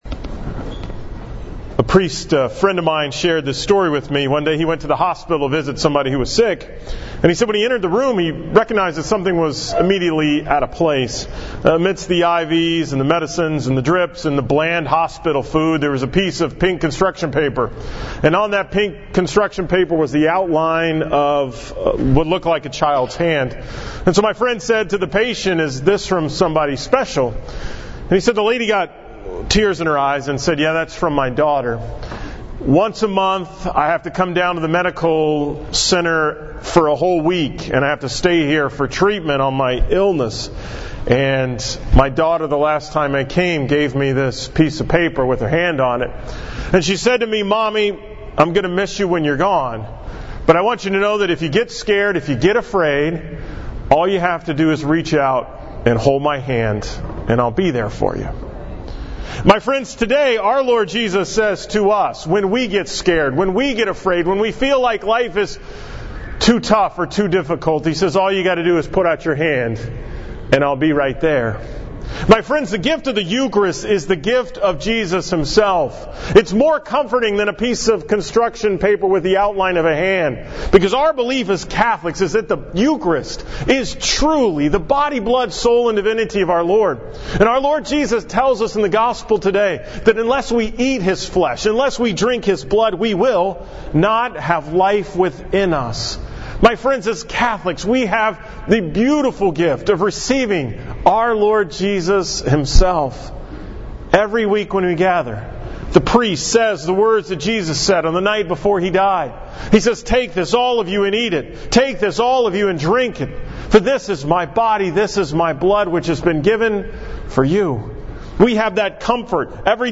From the 8 am Mass at Our Mother of Mercy on Sunday, June 18th, 2017